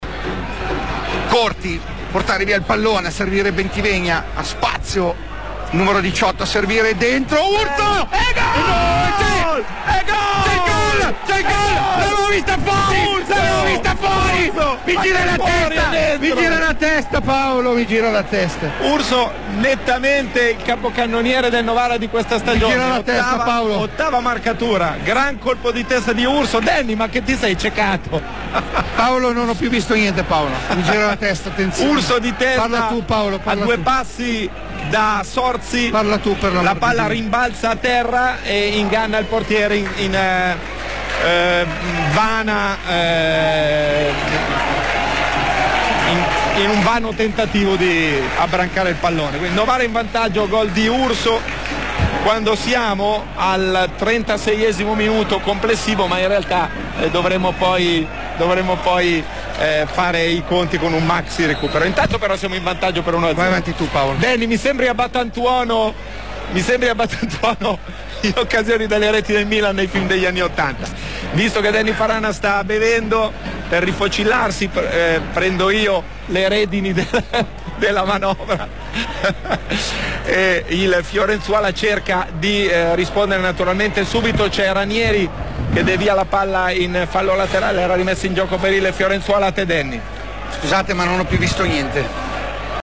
Rivivi con noi l'emozione dei tre gol azzurri della gara Fiorenzuola-Novara, direttamente dalla radiocronaca di Radio Azzurra: